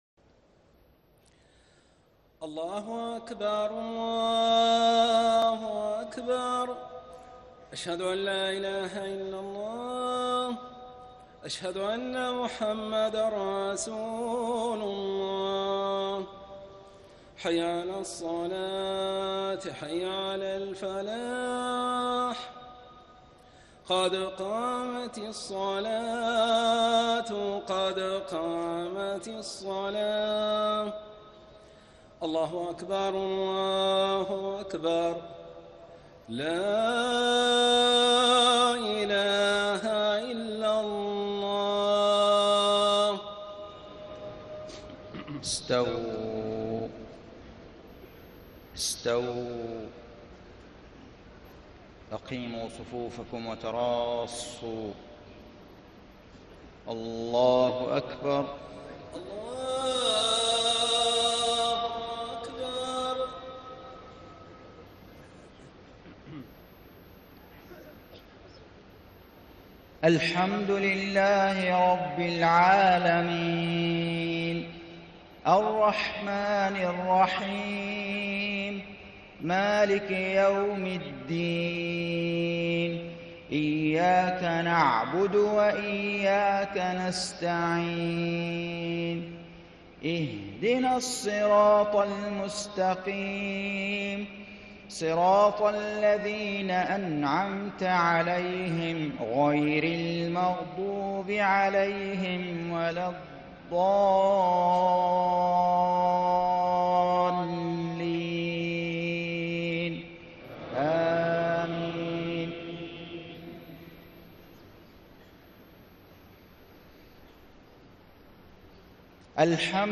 صلاة المغرب 1-1-1436هـ من سورة فاطر > 1436 🕋 > الفروض - تلاوات الحرمين